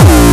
VEC3 Bassdrums Dirty 14.wav